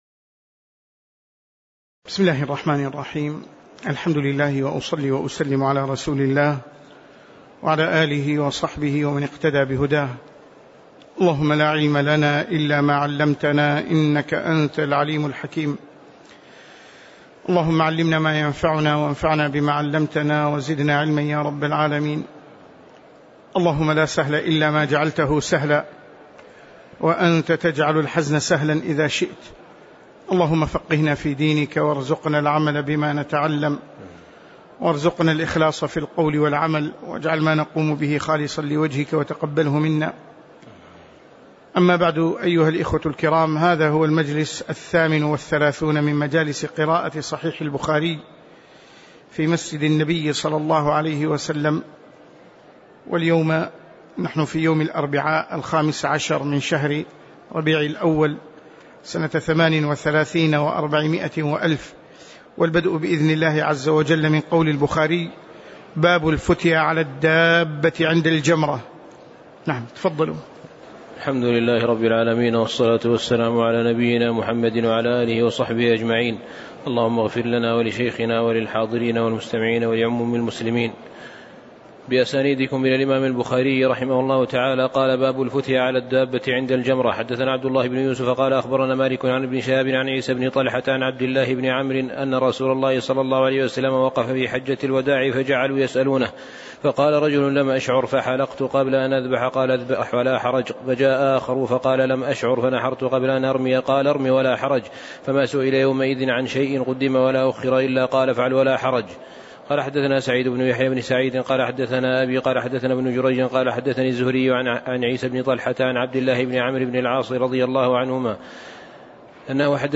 تاريخ النشر ١٥ ربيع الأول ١٤٣٨ هـ المكان: المسجد النبوي الشيخ